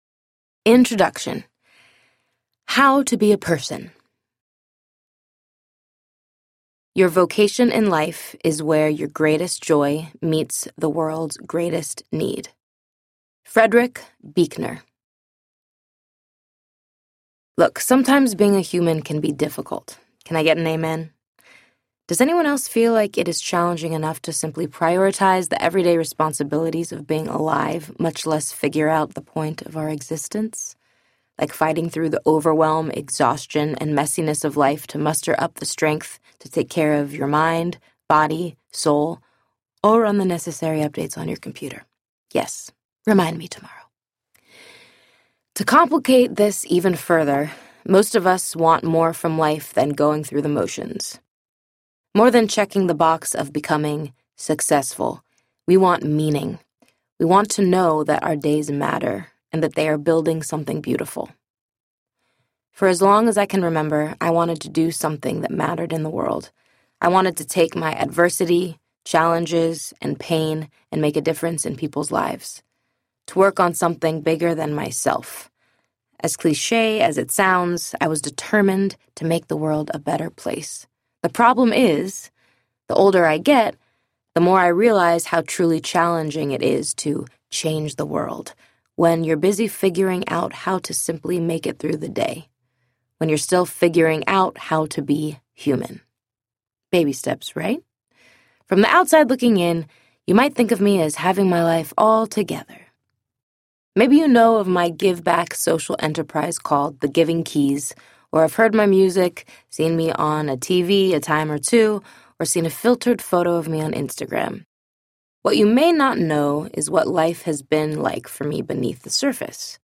You Are the Key Audiobook